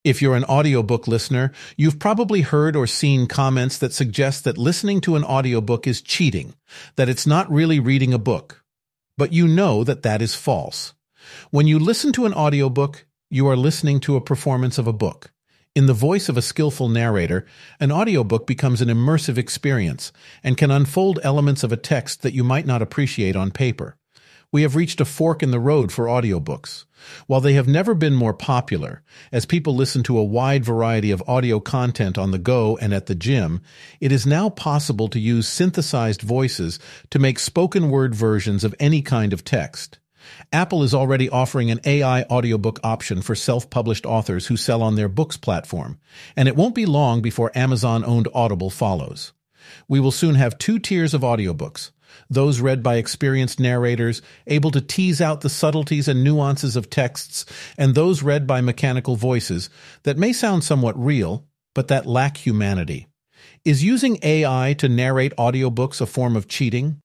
This one is from Eleven Labs, which offers AI voices, and the ability to create a voice model from any voice.
The latter sounds much more realistic, but it does lack humanity.
AI-voice.mp3